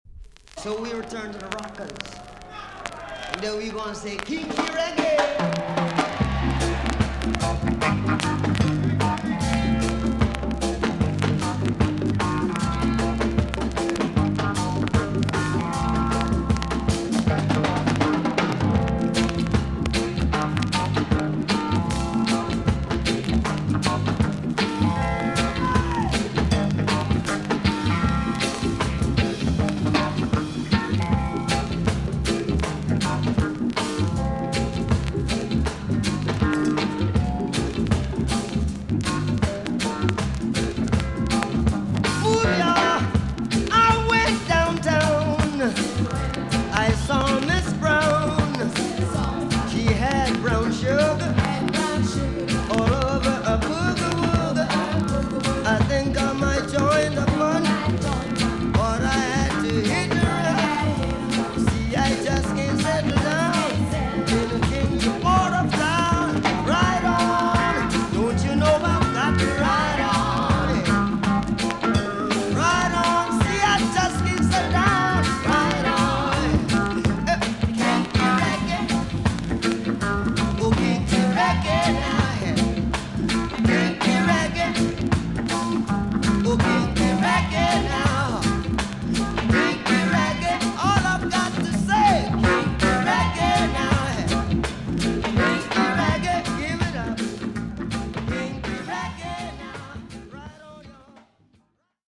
TOP >REGGAE & ROOTS
VG+ 少し軽いチリノイズが入ります。